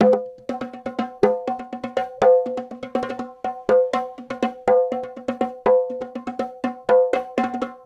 Also below in a faux Ableton-style interface are all the original loops used to create the tracks.
Bongo.wav